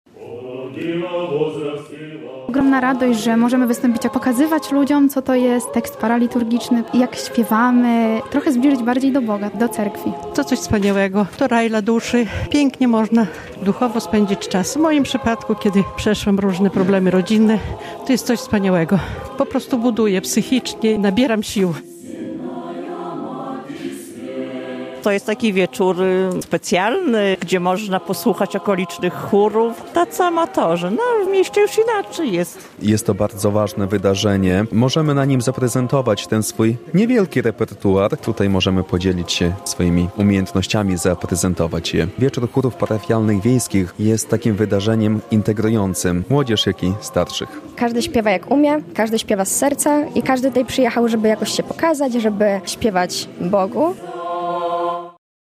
Można było usłyszeć pieśni paraliturgiczne, ale też kompozycje ludowe i współczesnych kompozytorów. W cerkwi p.w. apostoła i ewangelisty Jana Teologa w Nowoberezowie odbył się Wieczór Wiejskich Chórów Parafialnych. To już siódma edycja tego wydarzenia, które ma chronić od zapomnienia śpiewy związane z prawosławną tradycją.